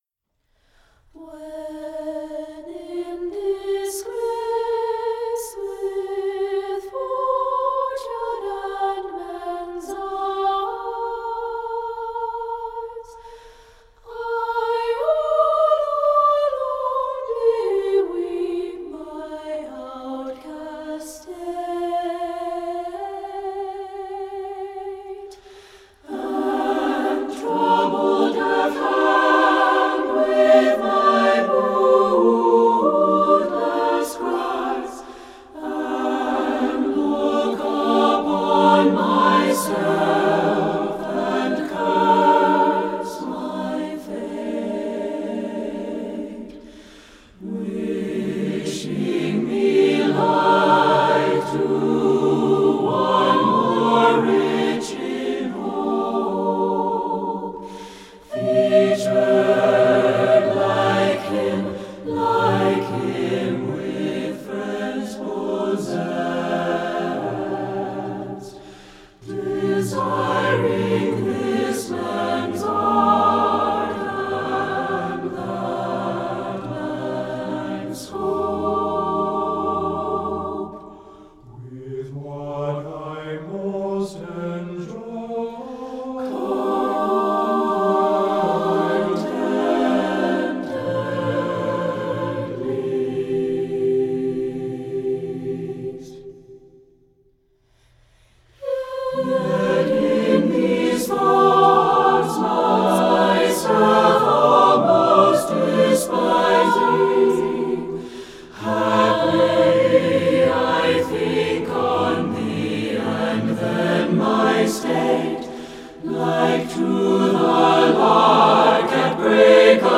lawson gould choral